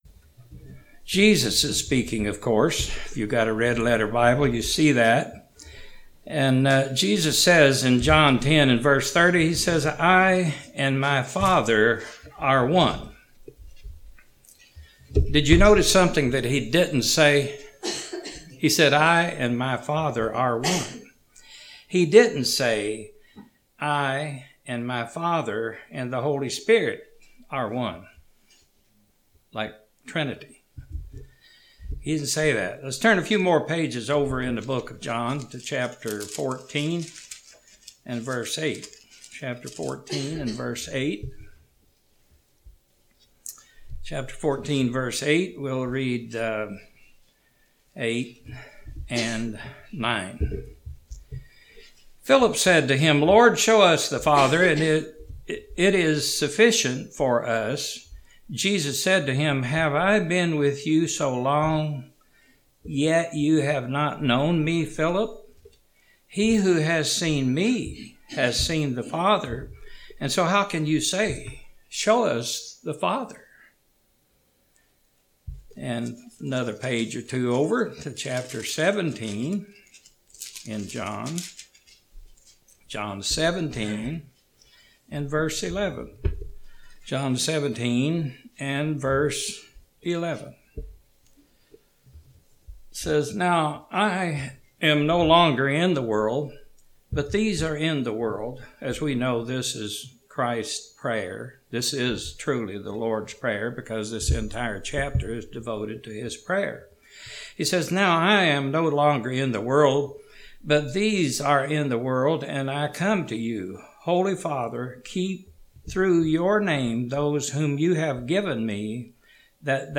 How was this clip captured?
(Missing first 4-5 minutes ...) Given in Knoxville, TN